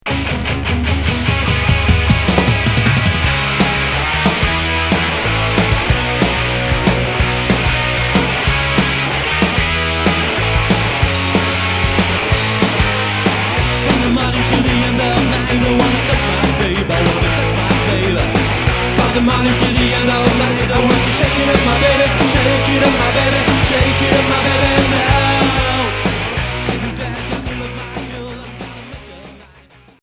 Garage punk